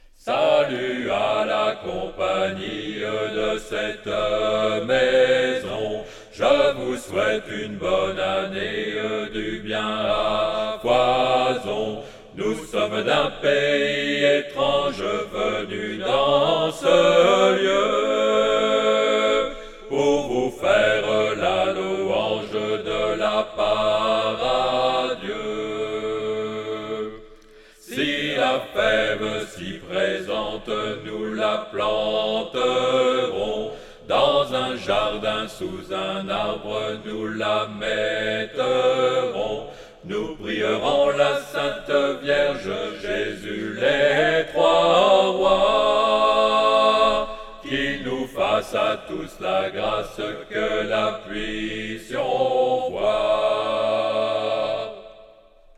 traditionnel
Tenor
à 4 voix